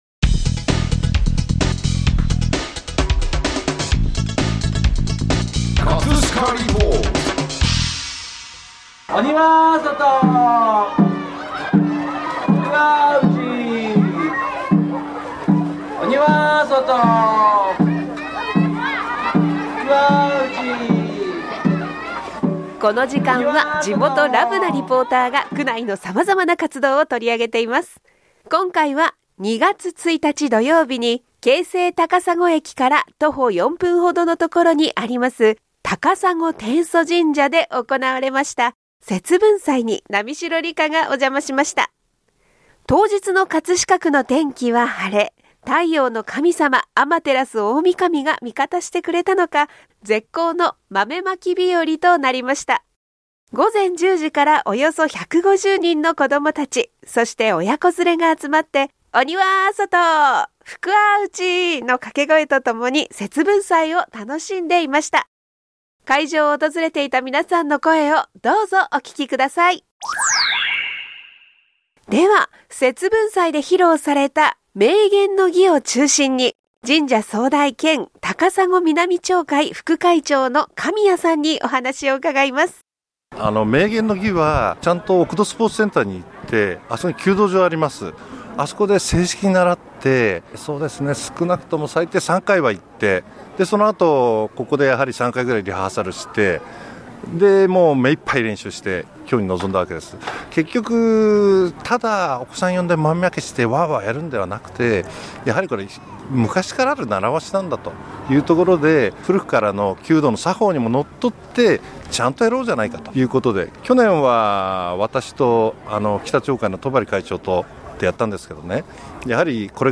【葛飾リポート】 今回は2月1日㈯に京成高砂駅から徒歩4分ほどのところにあります高砂天祖神社で行われました「…